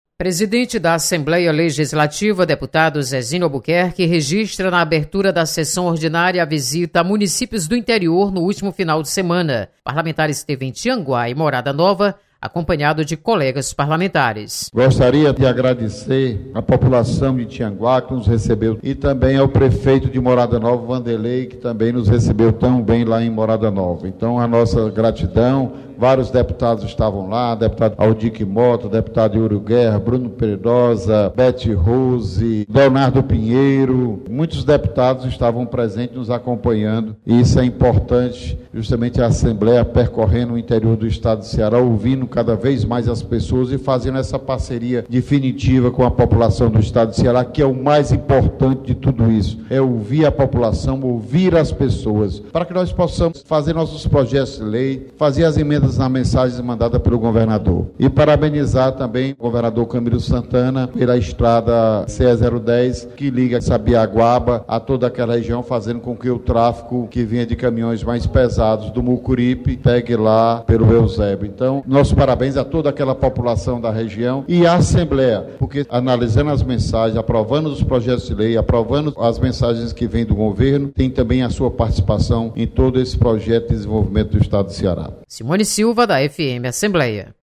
Presidente Zezinho Albuquerque registra visitas aos municípios do Interior. Repórter